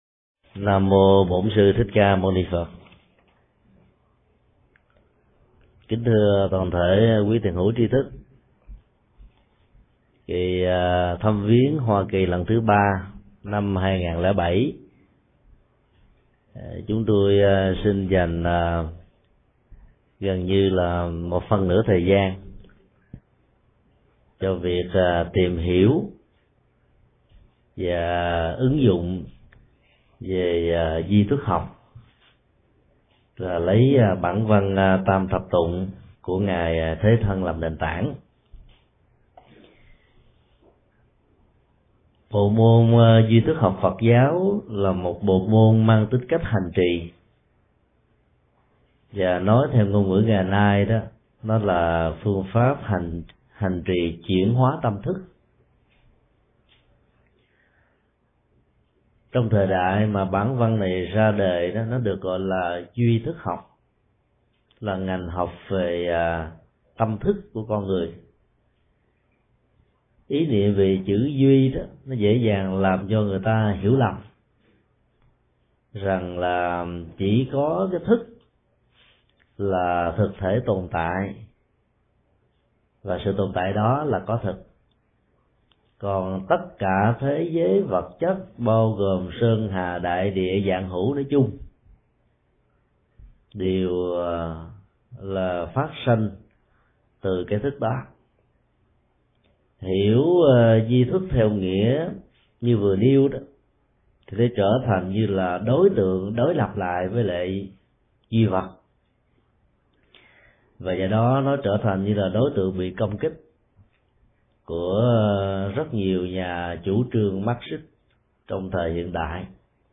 Mp3 Pháp Thoại Duy thức 1: Chủ thể & đối tượng – Thương Tọa Thích Nhật Từ giảng tại Đạo tràng Chúc Hảo, San Jose, ngày 16 tháng 6 năm 2007